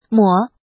怎么读
[ mǒ ]
mo3.mp3